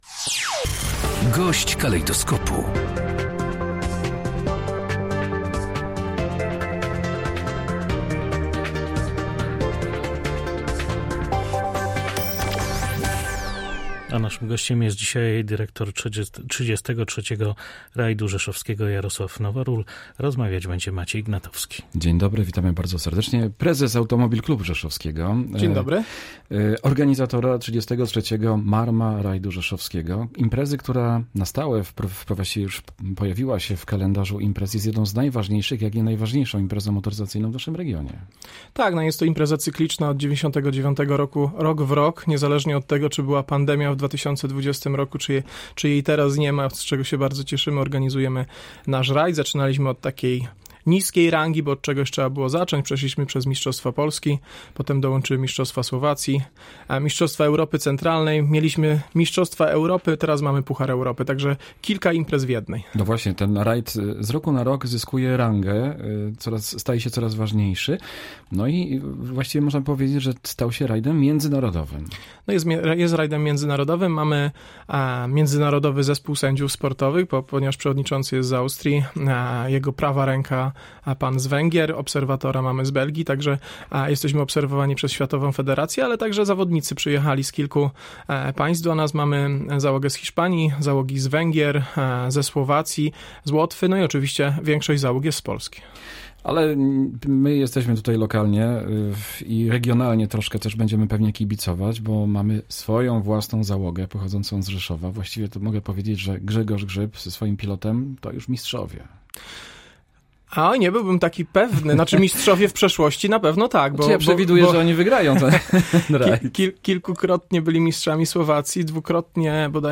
GOŚĆ DNIA. 93 załogi zameldują się na starcie Rajdu Rzeszowskiego • Audycje • Polskie Radio Rzeszów